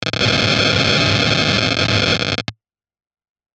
電撃 B02 long
/ F｜演出・アニメ・心理 / F-30 ｜Magic 魔法・特殊効果 / 電気
ゴゴゴ